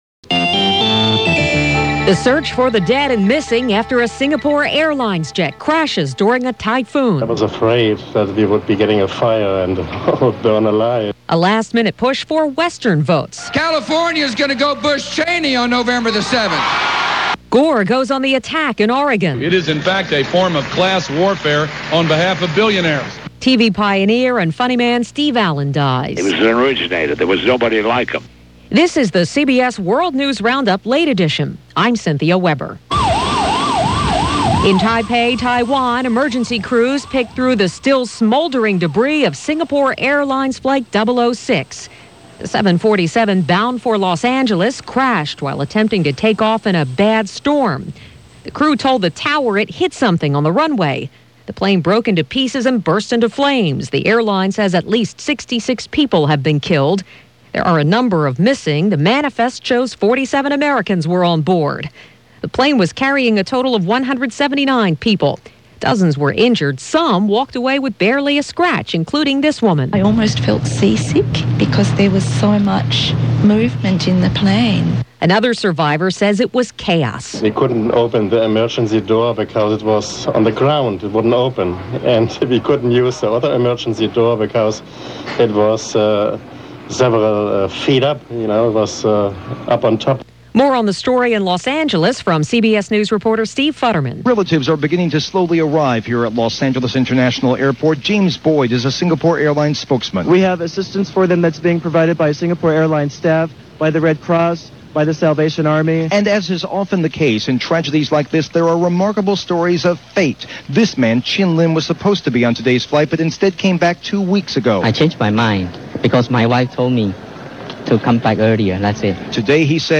And that’s a small slice of what went on, this October 31, 2000 as presented by The CBS World News Roundup; Late Edition